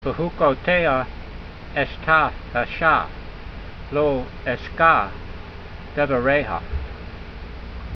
esh-ta-ah-sha
v16_voice.mp3